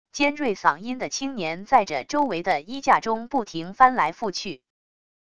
尖锐嗓音的青年在着周围的衣架中不停翻来覆去wav音频